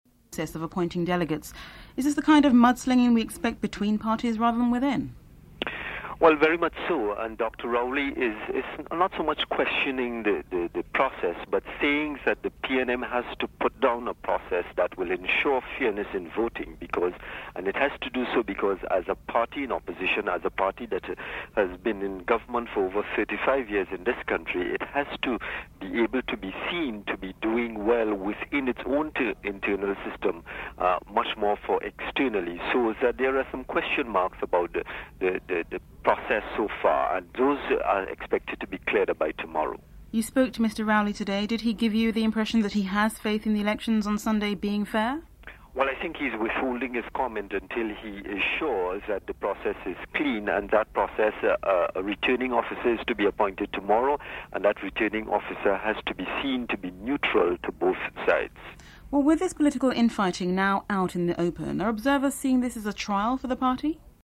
Report commences during segment one.